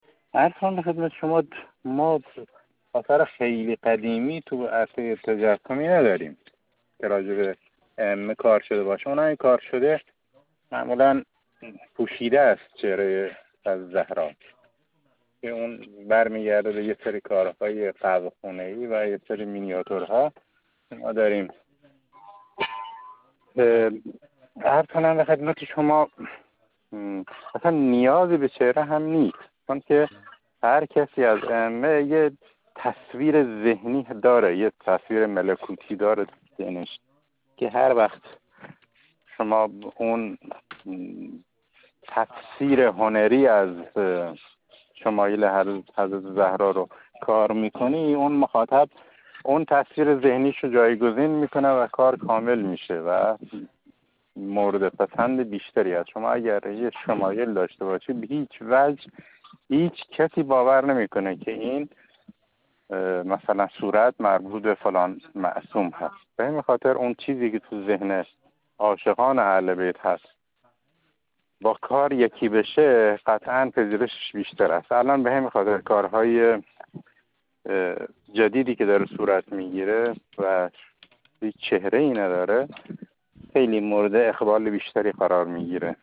یک نقاش